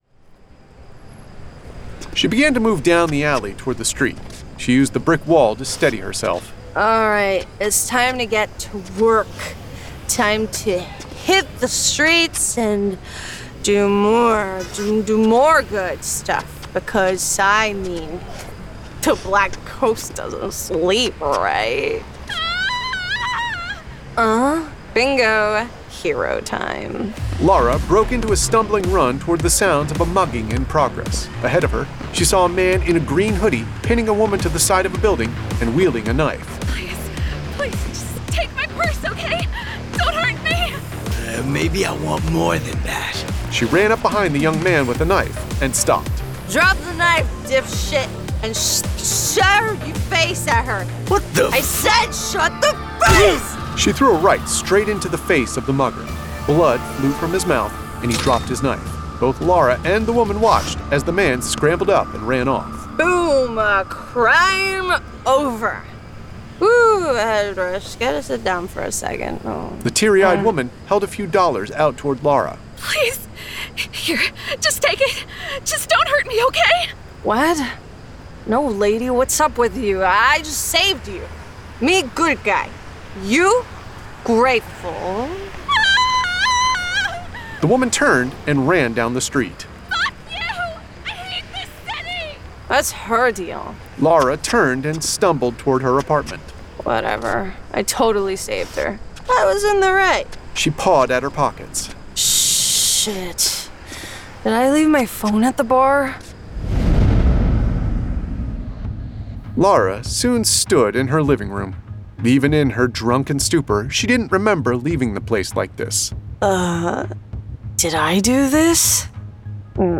Full Cast. Cinematic Music. Sound Effects.
[Dramatized Adaptation]
GraphicAudio presents THE BLACK GHOST produced with a full cast of actors, immersive sound effects and cinematic music!